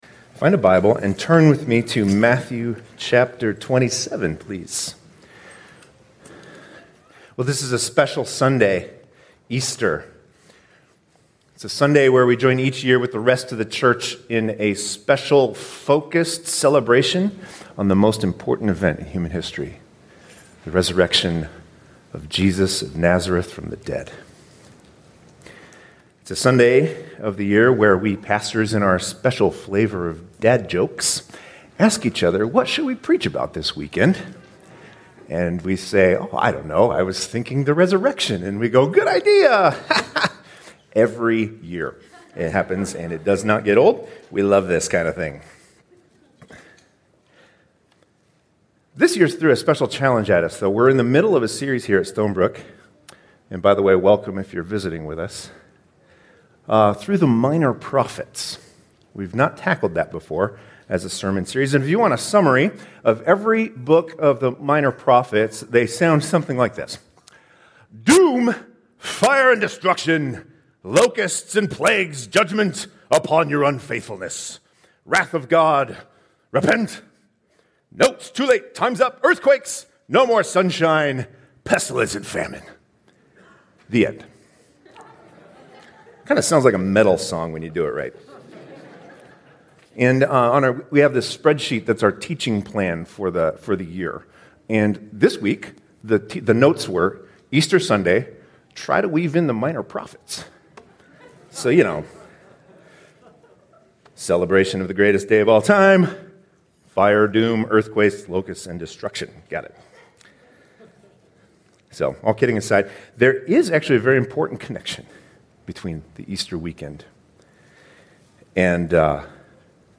2021 Stay up to date with “ Stonebrook Church Sermons Podcast ”